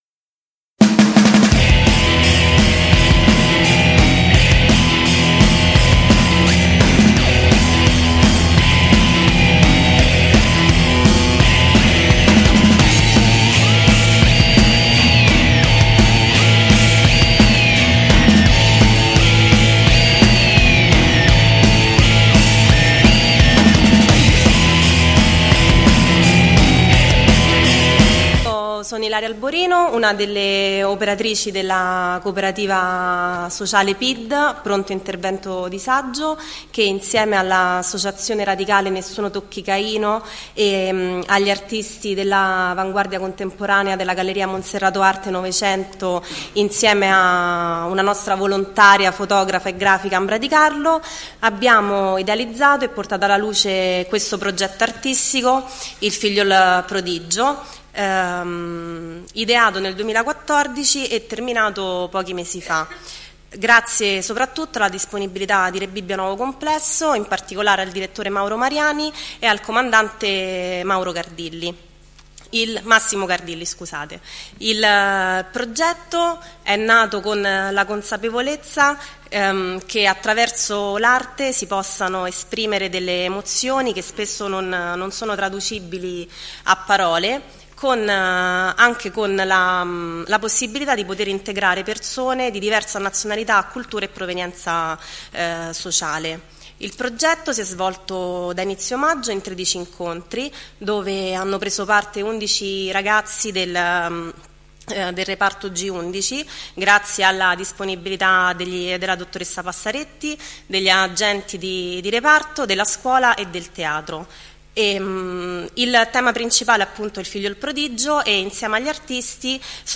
Presentazione dell'iniziativa.